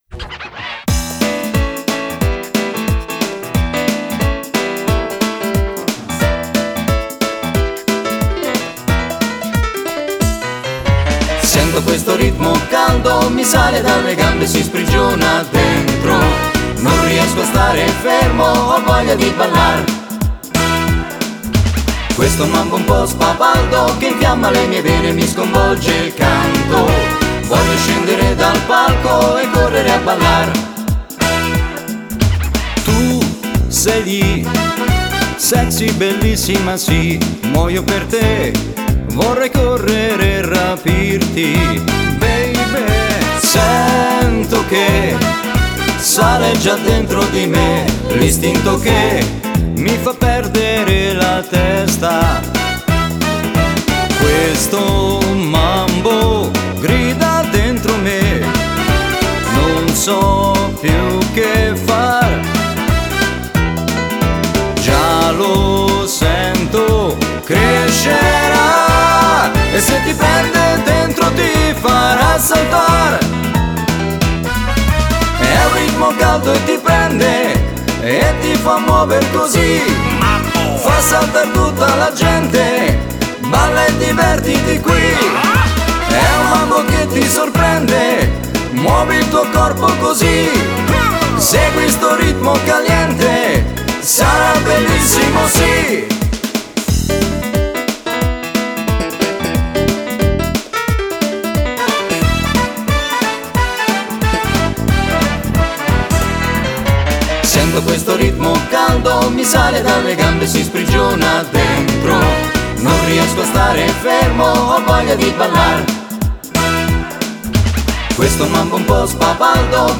e 12 ballabili per Fisarmonica solista